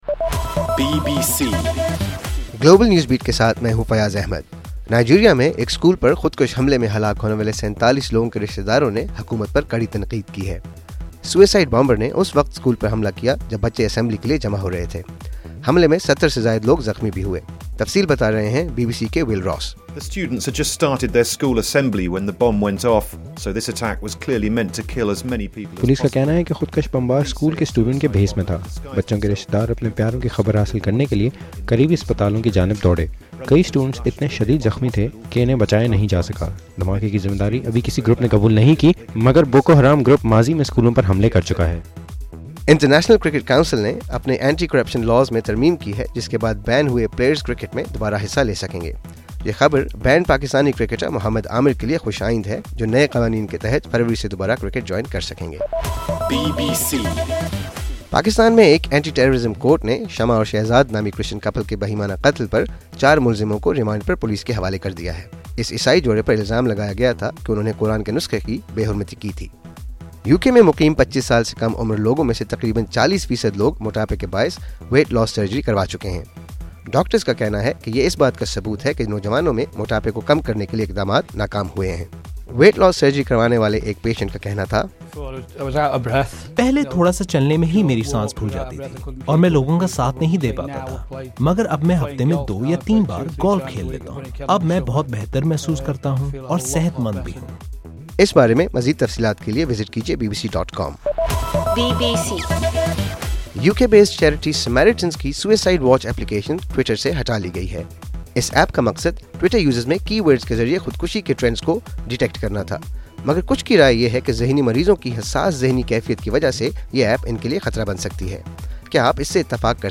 نومبر11 : صبح ایک بجے کا گلوبل نیوز بیٹ بُلیٹن